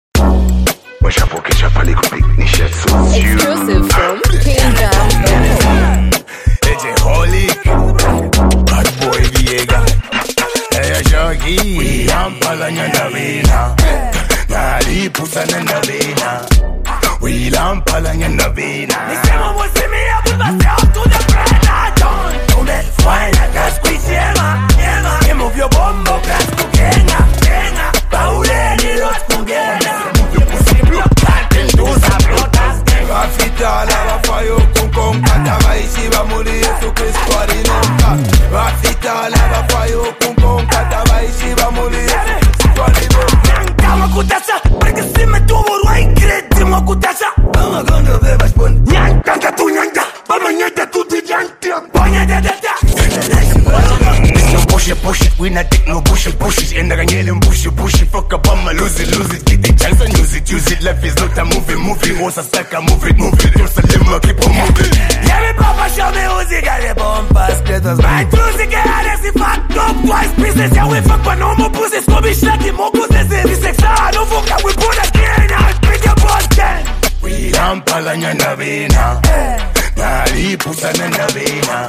Each artist brings a unique flavor to the track.